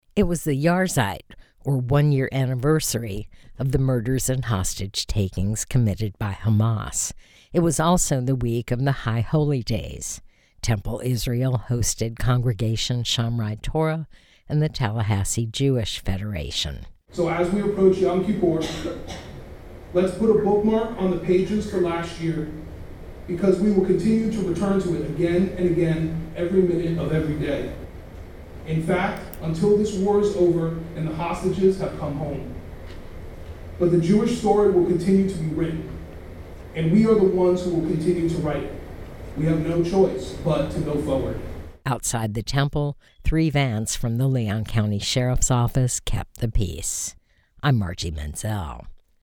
October 7th was a somber night at Tallahassee’s Temple Israel, as Jews and their allies gathered to remember the Hamas attack on Israel and honor those lost.
They sang, wept and prayed.